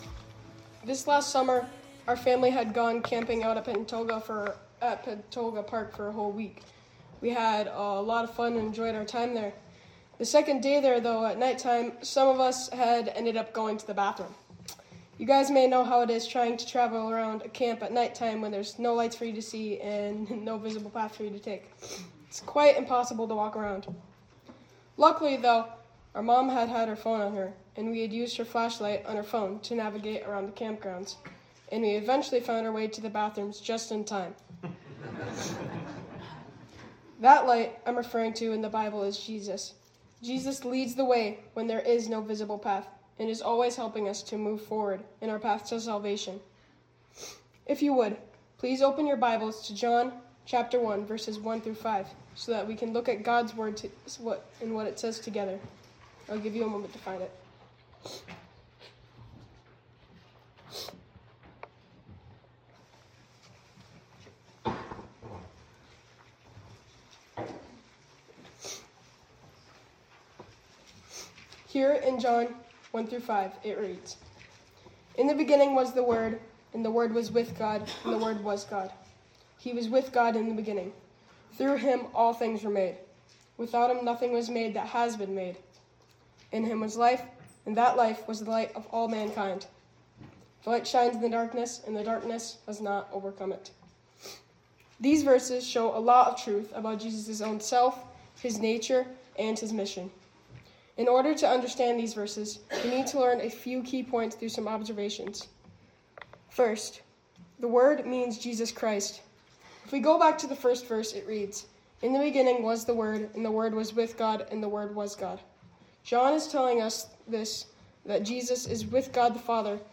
Speaker Guest Speaker